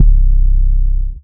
808 - Pull Up.wav